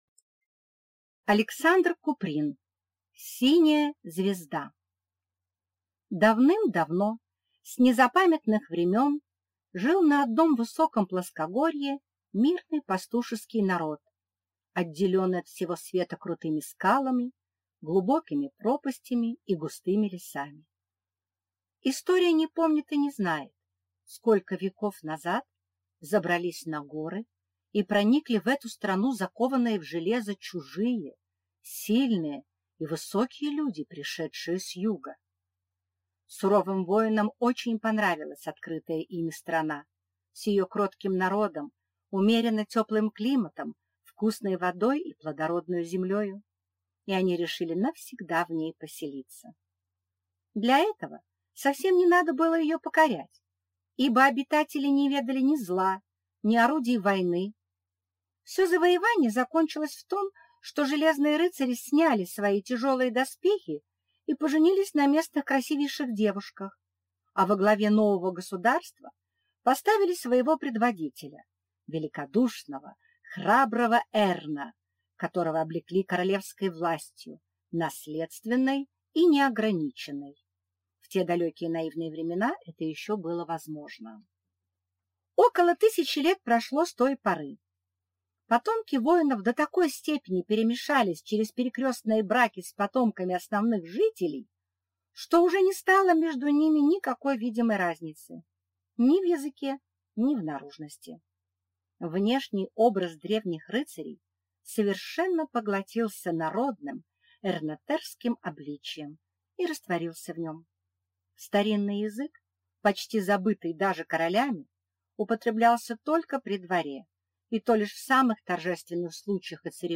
Аудиокнига Синяя звезда | Библиотека аудиокниг